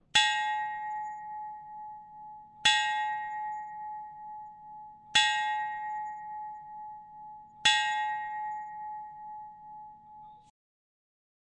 教堂钟声 - 声音 - 淘声网 - 免费音效素材资源|视频游戏配乐下载
用金属笔敲击灭火器，产生类似教堂钟的振动。